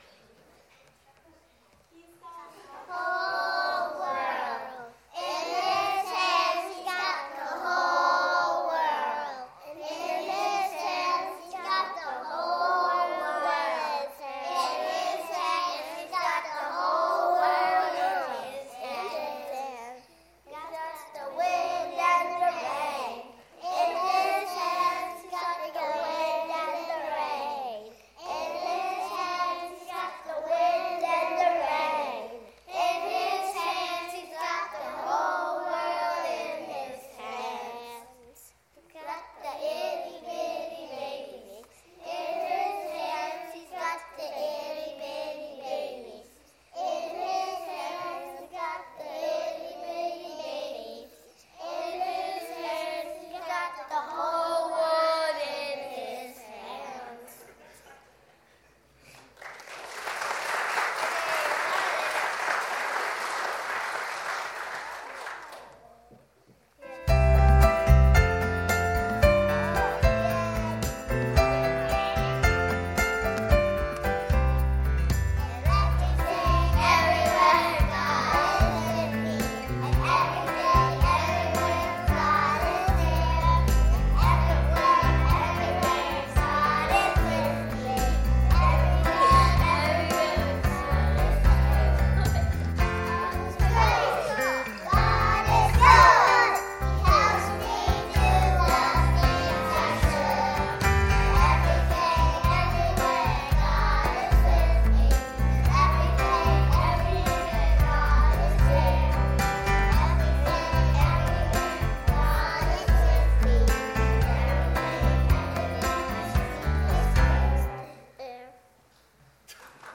Sunday Morning Music
God's Kids singing and playing bells